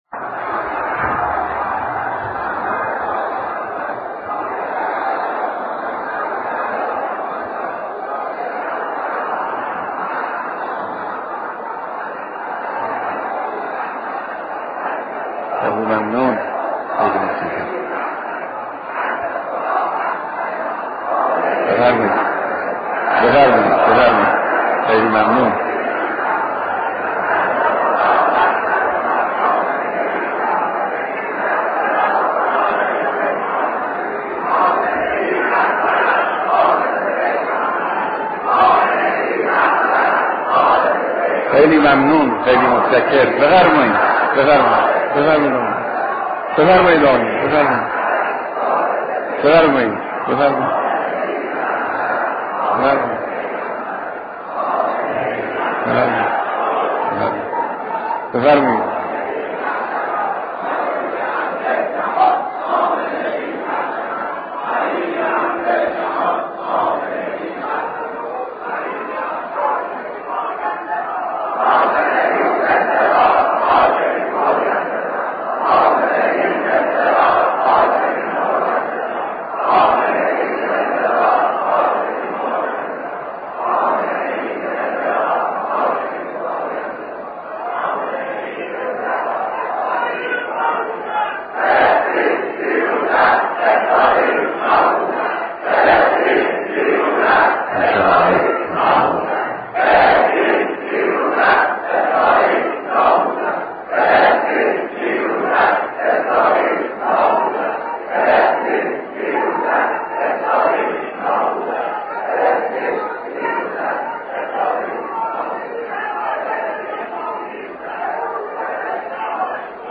بيانات در خطبههاى نماز جمعه تهران
سخنرانی